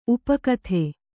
♪ upakathe